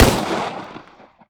Gunshot.wav